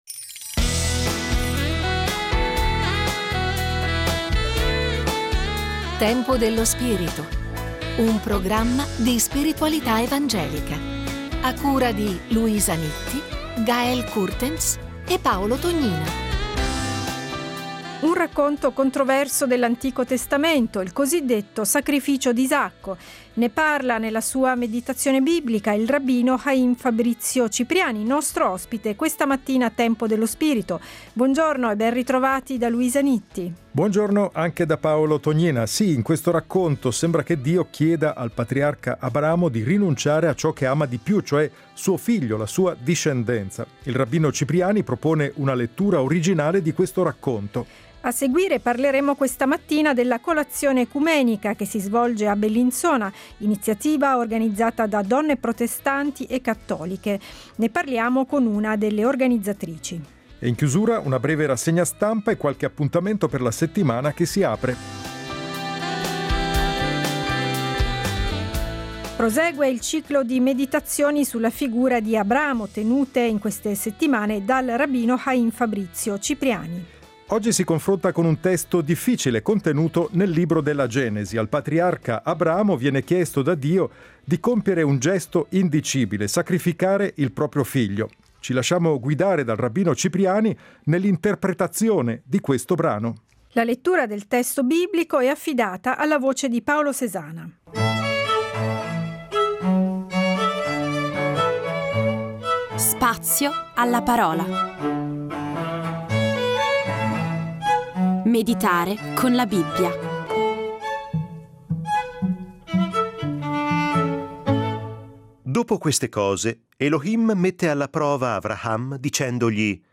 -Rassegna stampa.